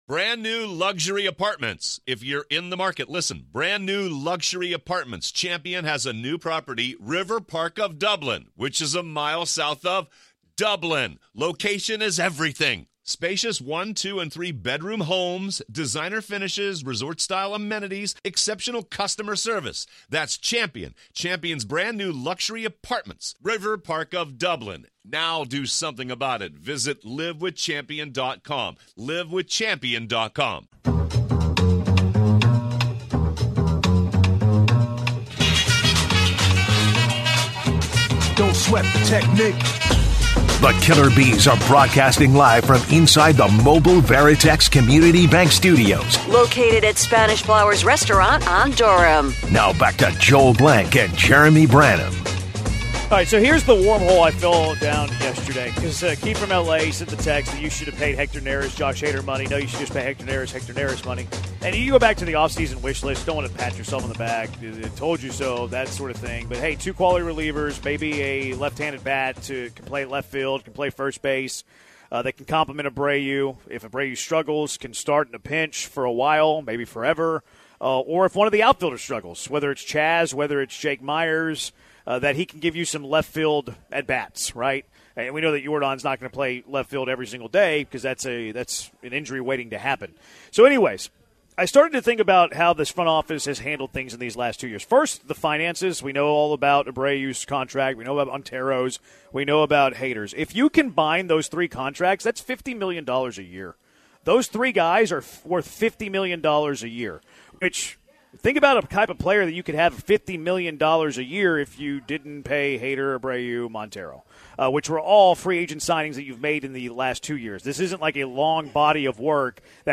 LIVE from Spanish Flowers!